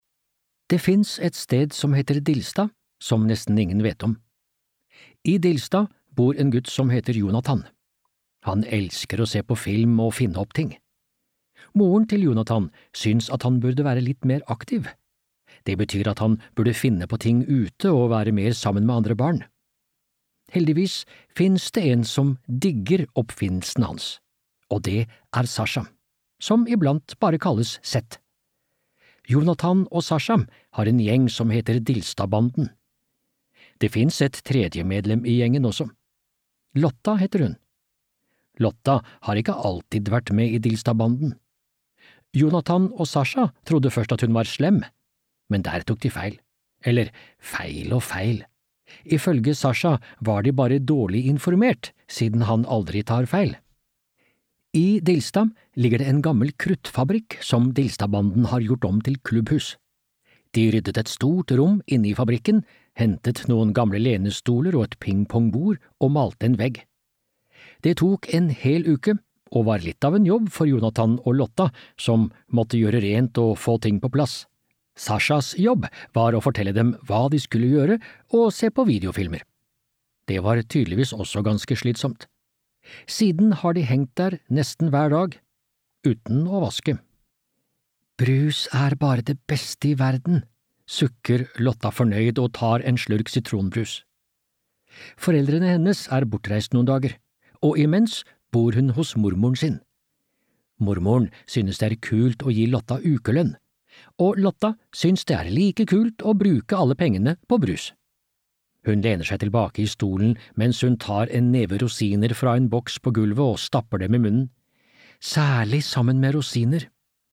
Hamsterkuppet (lydbok) av Hedda Lapidus